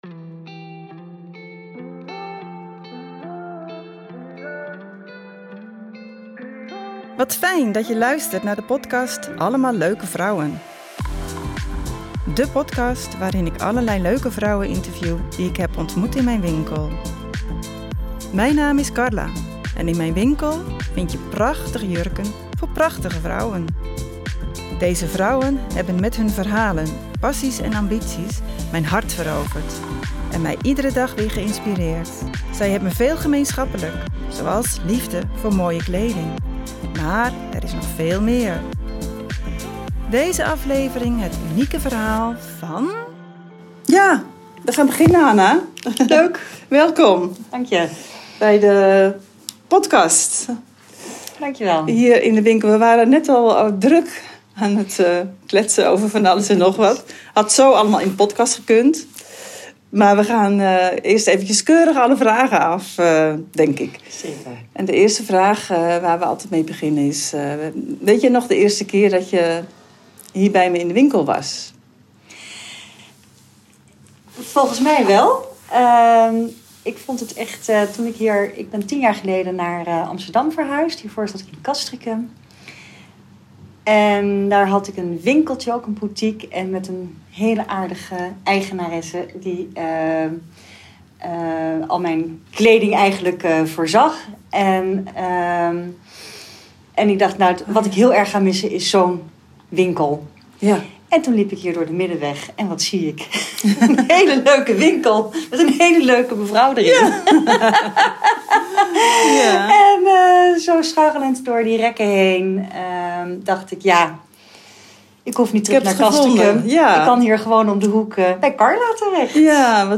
Allemaal leuke vrouwen is de podcast waarin allerlei bijzonder leuke vrouwen worden geinterviewd over hun werk, hun leven en ambities. En over de rol van kleding in hun leven.